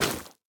Minecraft Version Minecraft Version latest Latest Release | Latest Snapshot latest / assets / minecraft / sounds / block / netherwart / break6.ogg Compare With Compare With Latest Release | Latest Snapshot
break6.ogg